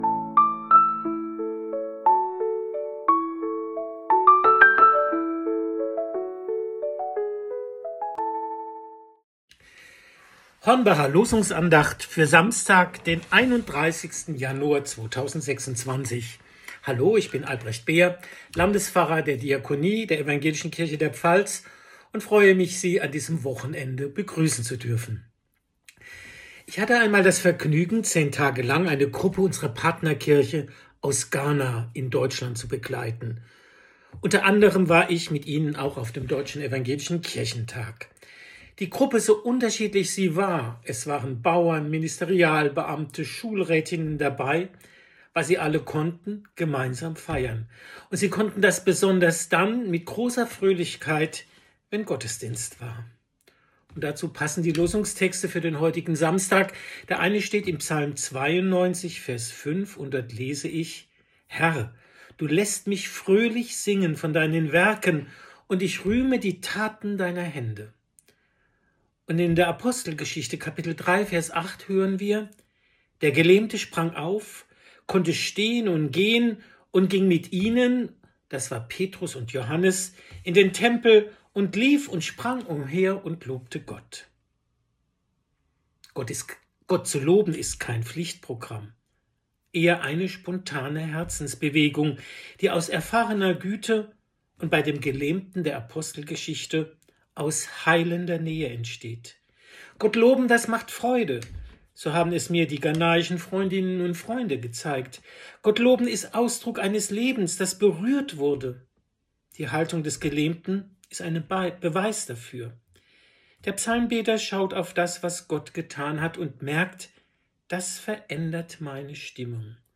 Losungsandacht für Samstag, 31.01.2026